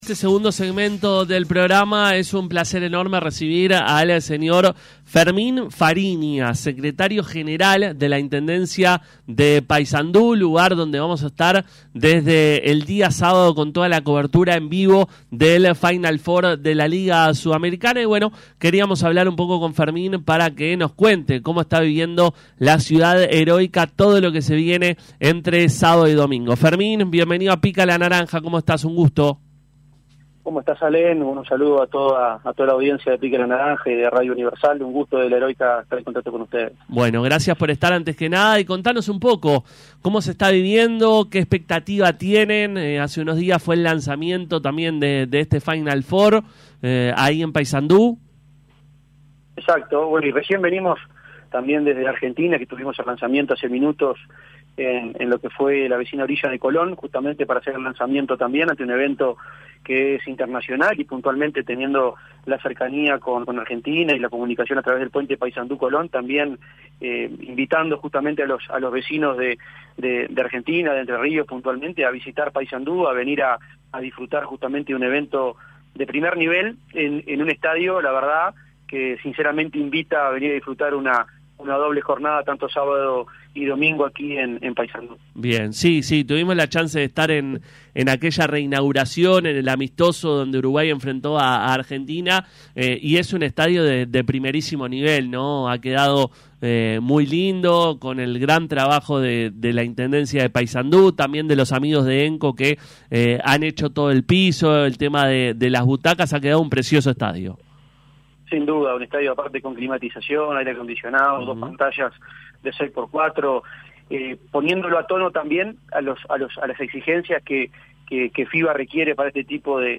Fermín Farinha, secretario general de la Intendencia de Paysandú habló con Pica La Naranja sobre la Liga Sudamericana que se va a jugar en el deprtamento el próximo fin de semana.